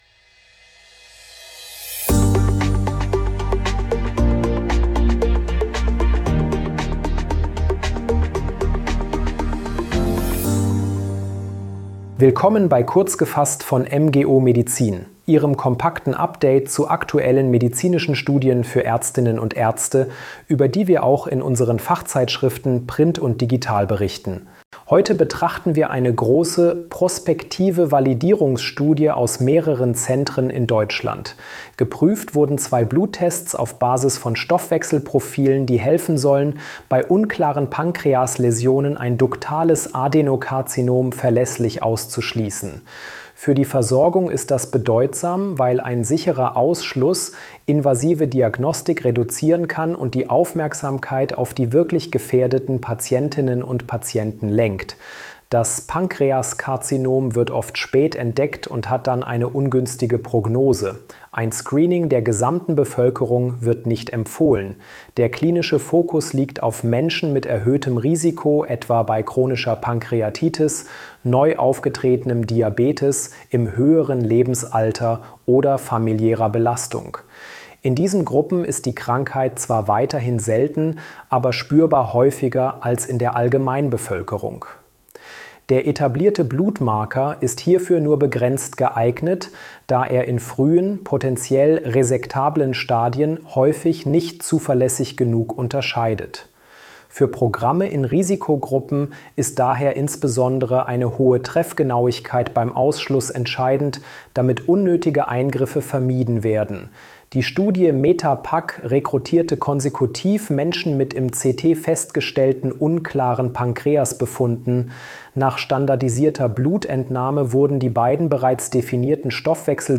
mit Unterstützung von Künstlicher Intelligenz erstellt.